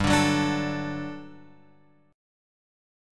GM7sus2 chord {3 5 4 x 3 5} chord